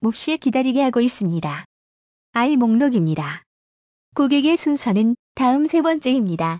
スピーカを設置すれば、受付した言語で音声アナウンスを流せますのでご案内業務も安心です。
韓国語版（例）電話呼出音声